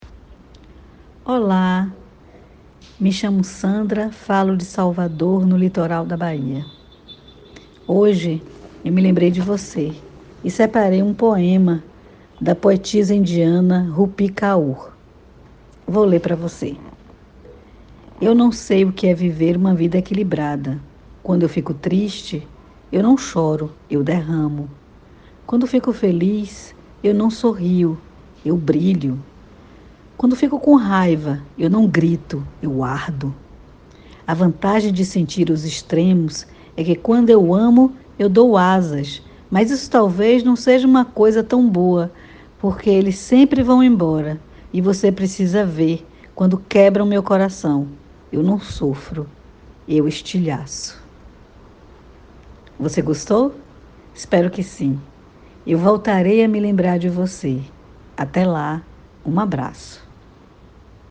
Poema Português
Narrado por